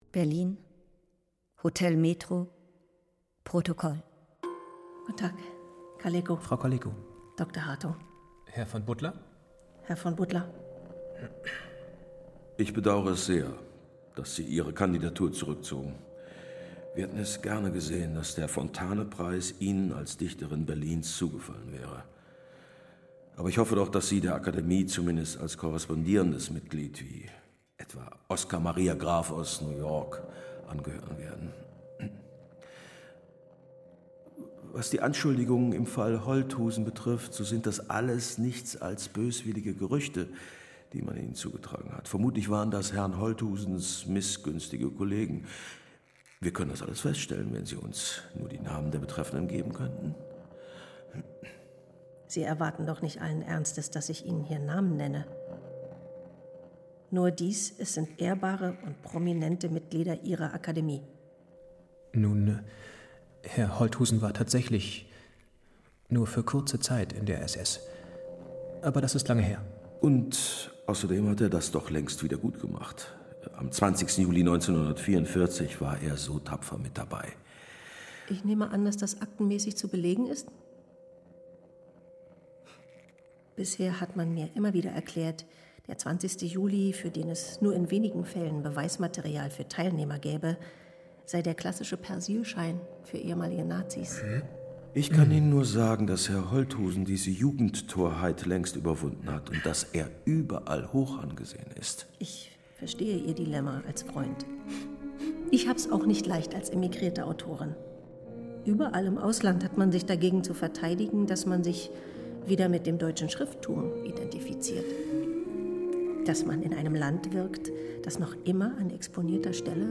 Nicht ist, sagte der Weise – musikalischens Hörspiel über Mascha Kaléko
Wie eine zweite Erzählebene erzeugt sie eine melancholische Grundstimmung, stellt eine besondere Nähe zur Dichterin her, kontrastiert deren manchmal ironisch freche Texte und unterstreicht die nachdenklichen, traurigen Momente.